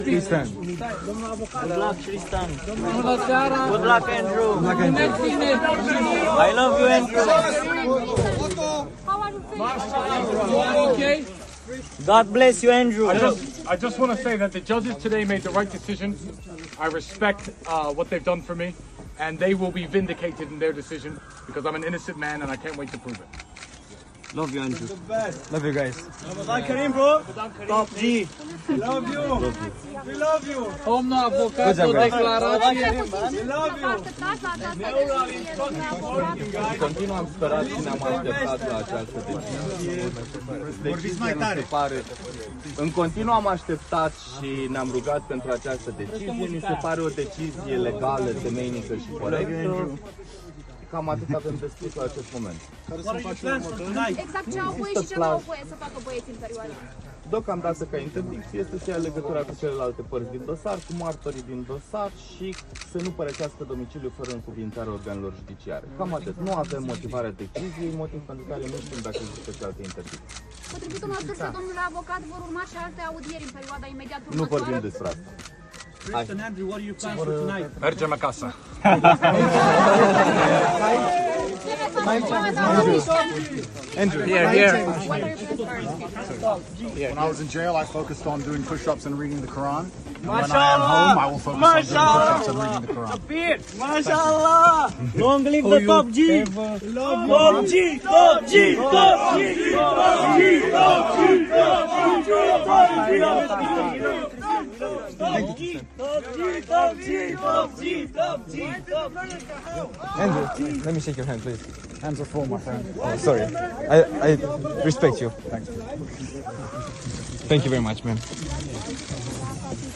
Andrew Tate’s FIRST INTERVIEW After Prison Release.mp3